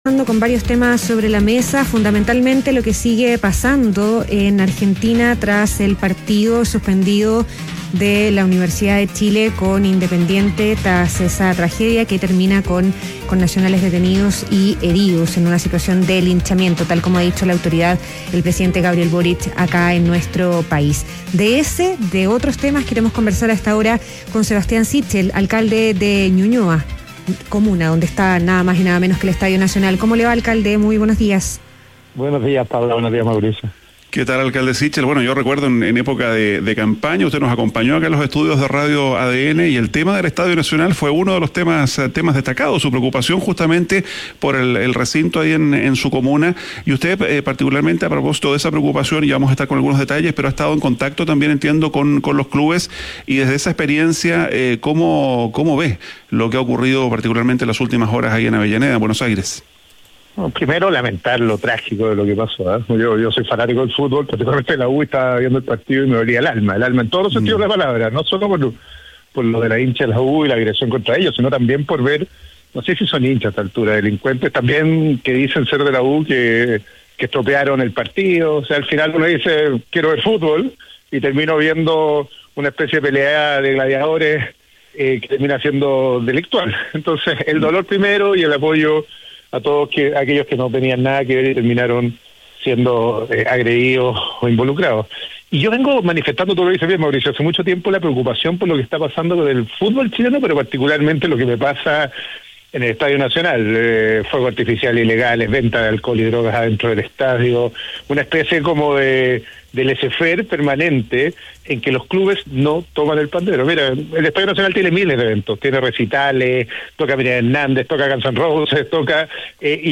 Entrevista a Sebastián Sichel, alcalde de Ñuñoa - ADN Hoy